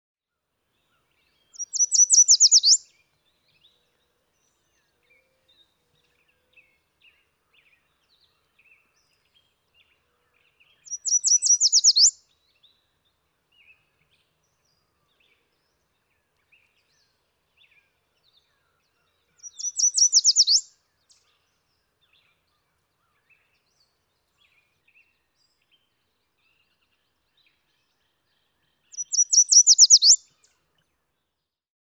Yellow Warbler | Hunterdon Art Museum
yellow-warbler.mp3